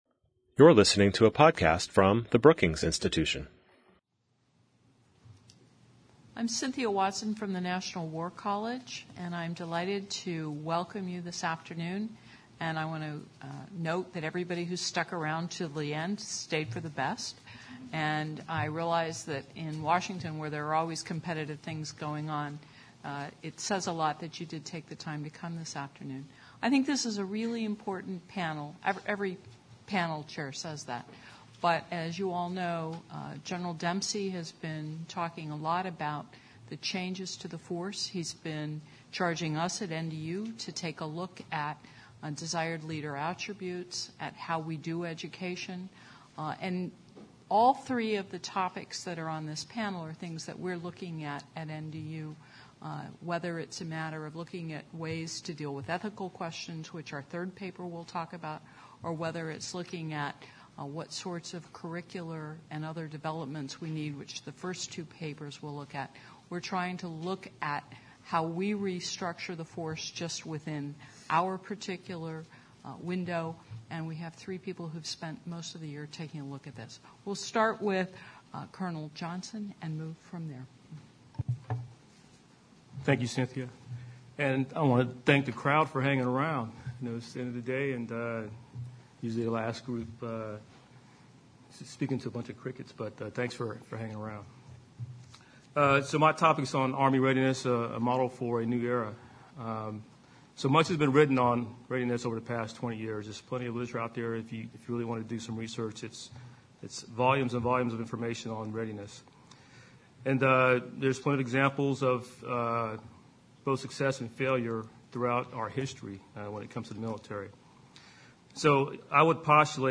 On March 12, the Center for 21st Century Security and Intelligence hosted the fifth annual Military and Federal Fellow Research Symposium.